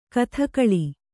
♪ kathakaḷi